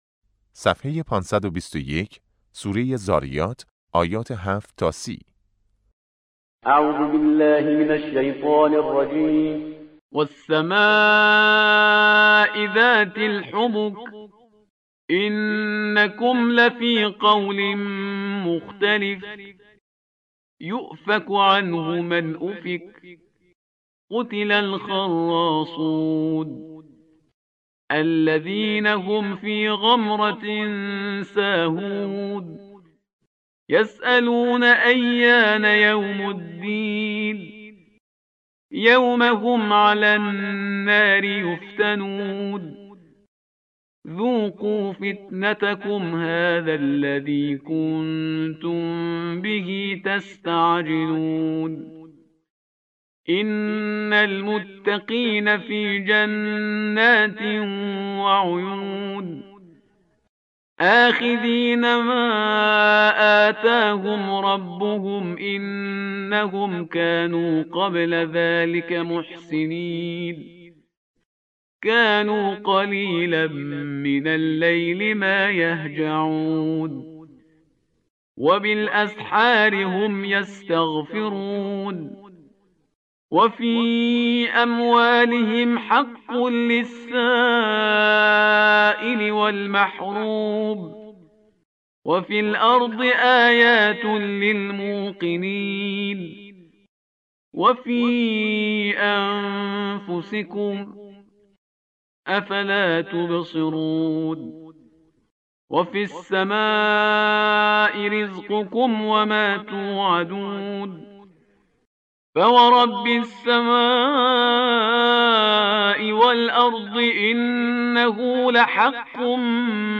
قرائت درس پنجم جلسه اول و دوم